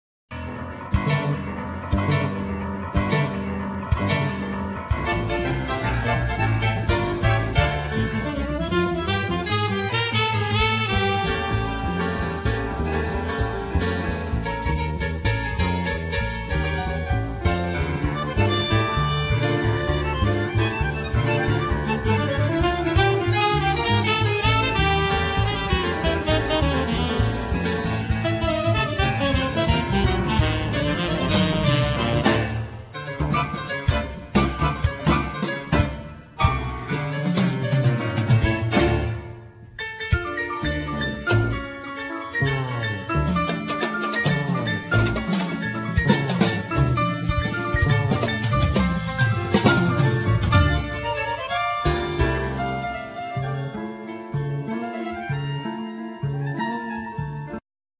Piano
Double Bass
Drums
Clarinet,Bass Clarinet
Bandoneon
Violin
Voice
Saxophone